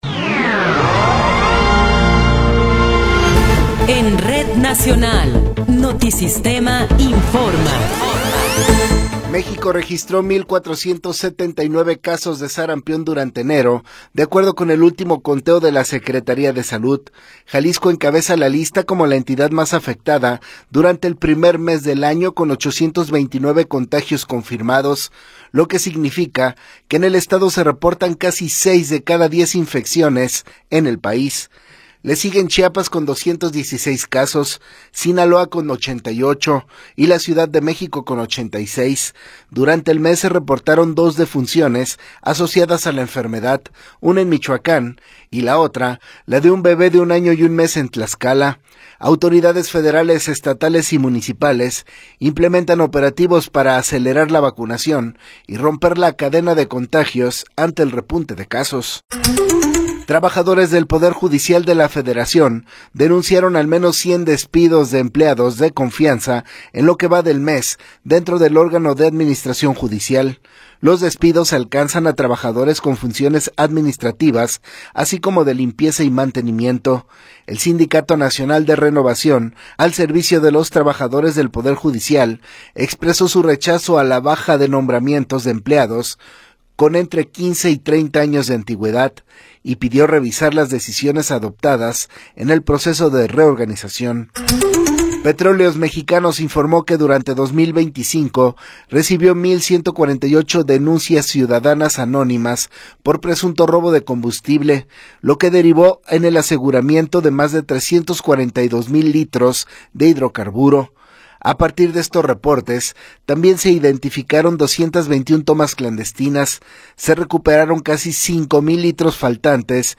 Noticiero 13 hrs. – 31 de Enero de 2026
Resumen informativo Notisistema, la mejor y más completa información cada hora en la hora.